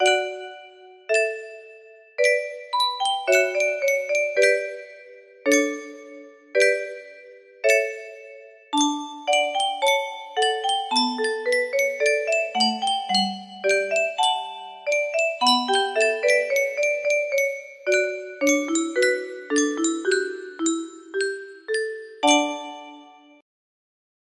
Barroco music box melody